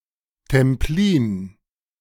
Templin (German: [tɛmˈpliːn]